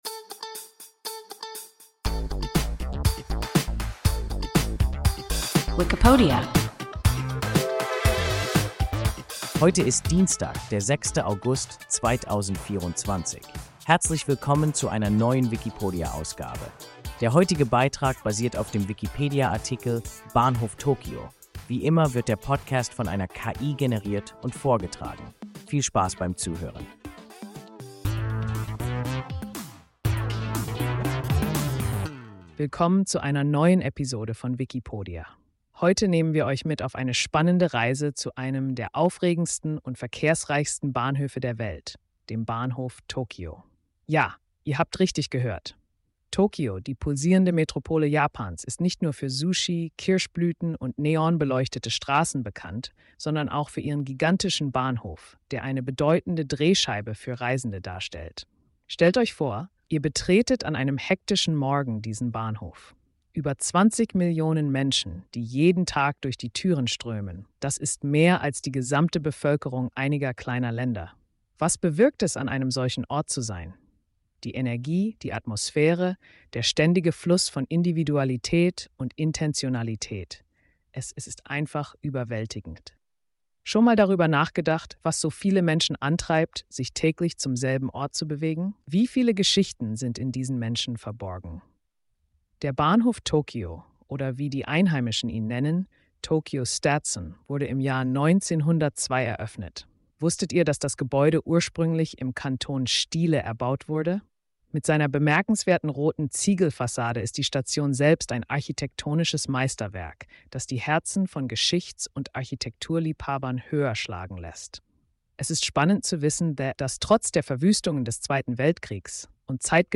Bahnhof Tokio – WIKIPODIA – ein KI Podcast